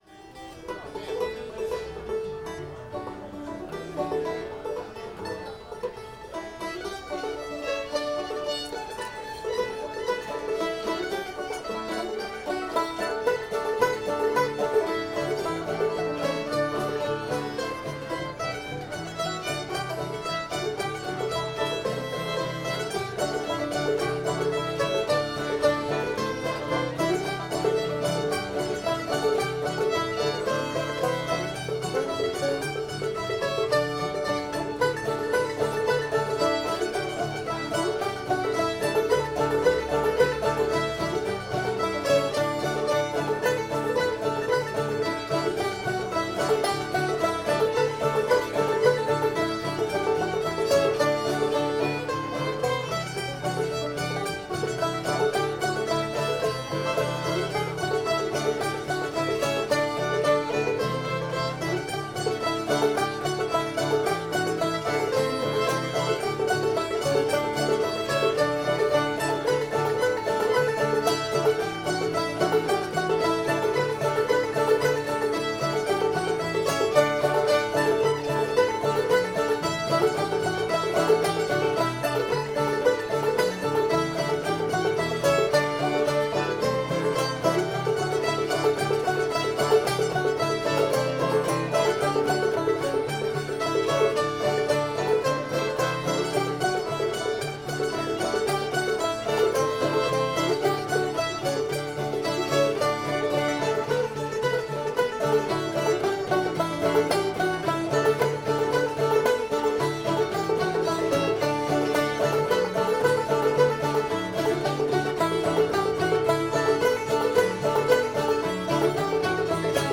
waiting for nancy [D]